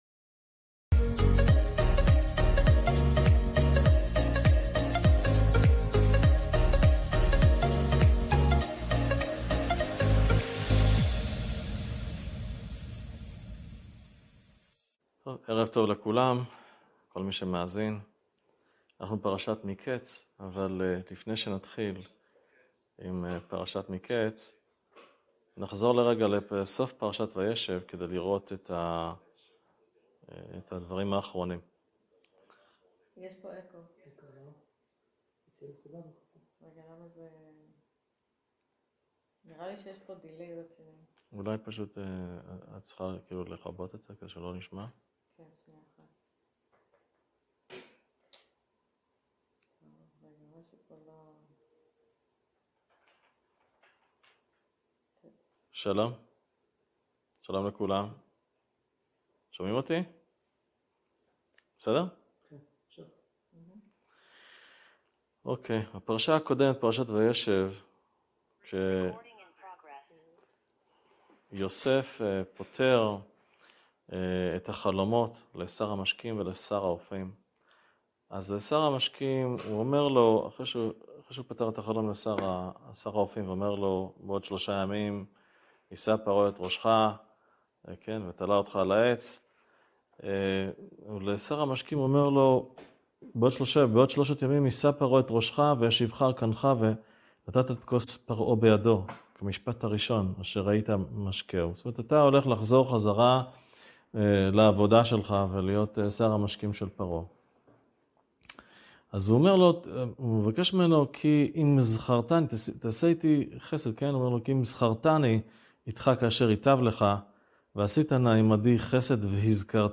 שיחה